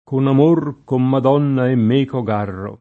kon am1r, kom mad0nna e mm%ko g#rro] (Petrarca)